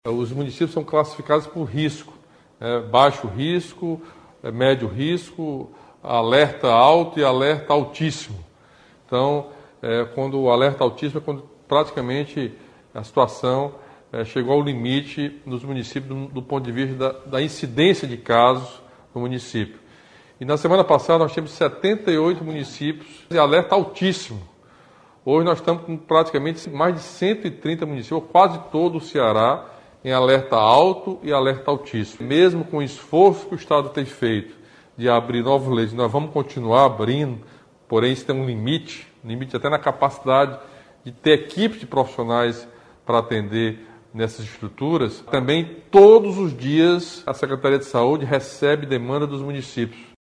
O governador Camilo Santana justificou que a decisão do Comitê, de estender o isolamento social rígido a todo o Estado, se deu pelos elevados números de municípios cearenses, mais de 130, estarem em alerta alto ou altíssimo do risco de contaminação pela Covid-19.